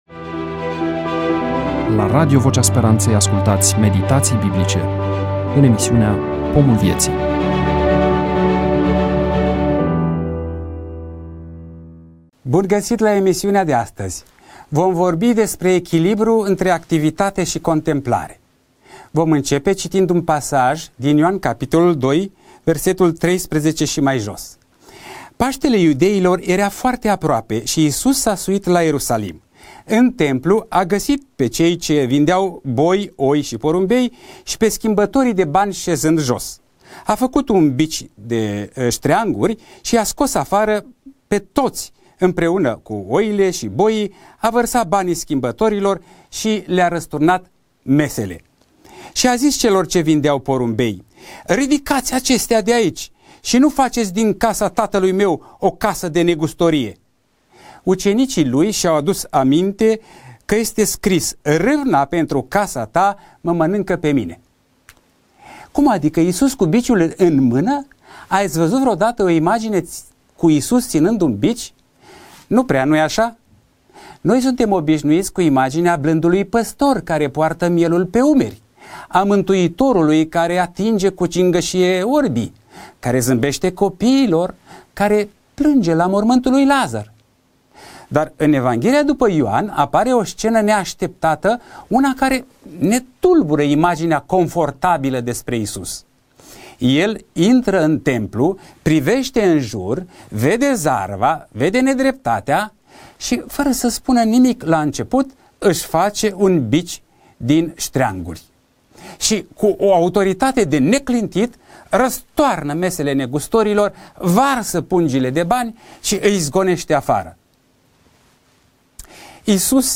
EMISIUNEA: Predică DATA INREGISTRARII: 21.11.2025 VIZUALIZARI: 16